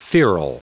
Prononciation du mot feral en anglais (fichier audio)
Prononciation du mot : feral